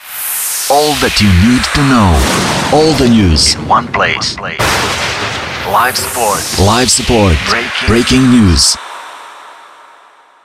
LS Breaking news vocal only.ogg